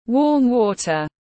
Nước ấm tiếng anh gọi là warm water, phiên âm tiếng anh đọc là /wɔːm ˈwɔː.tər/
Warm water /wɔːm ˈwɔː.tər/
Warm-water-.mp3